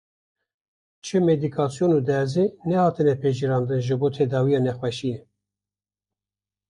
Pronounced as (IPA) /dɛɾˈziː/